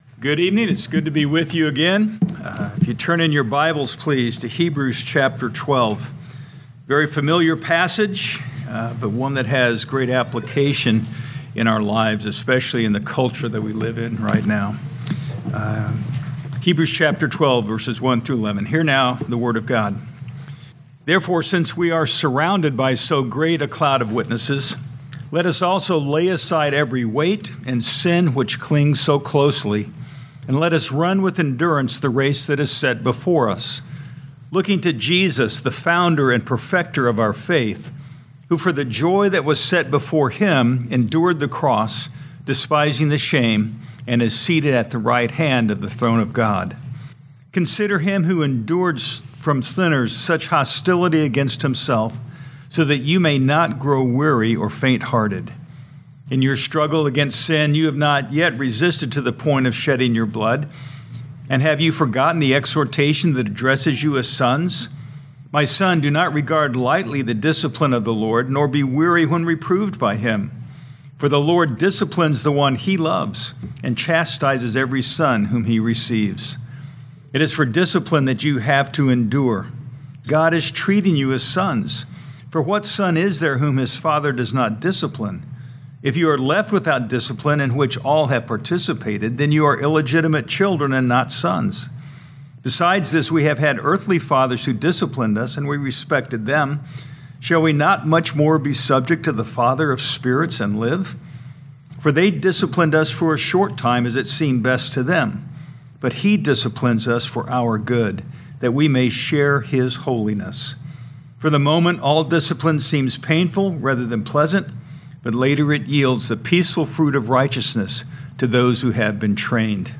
2025 Hebrews Evening Service Download